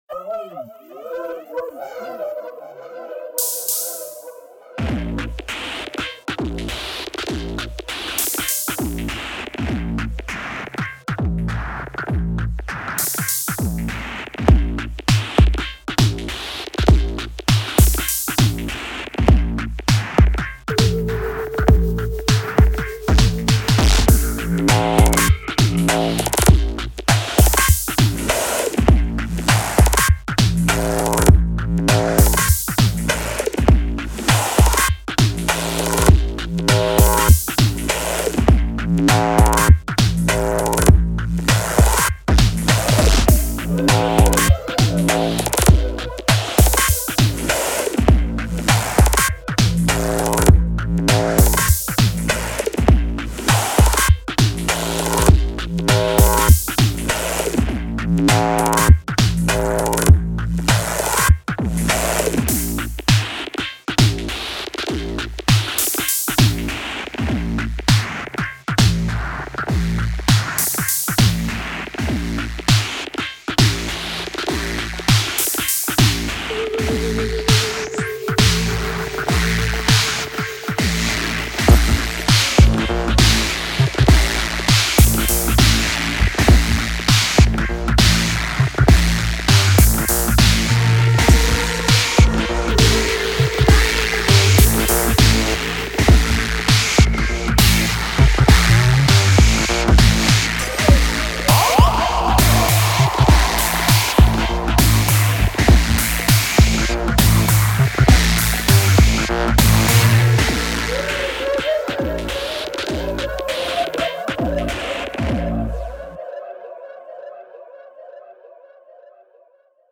A three part piece
(The Tonic even gets to play a few melody lines in there.)